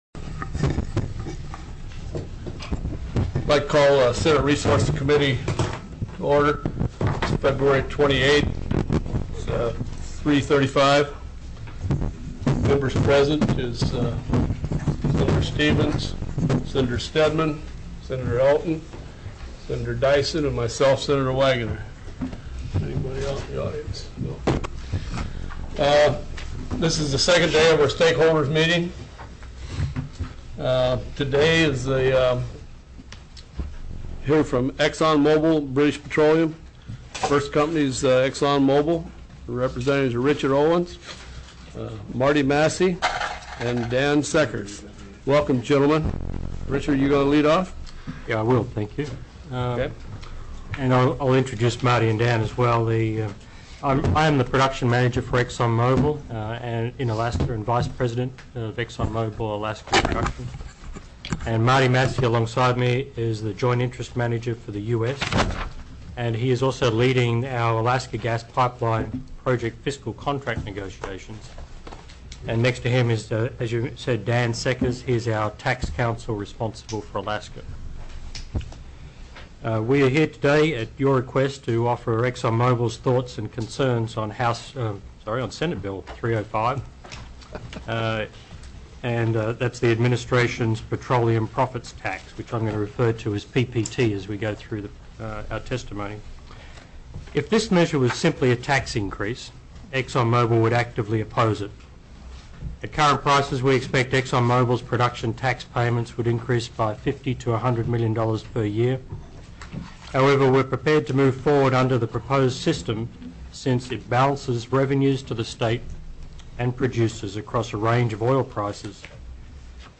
02/28/2006 03:30 PM Senate RESOURCES
Stakeholder Presentations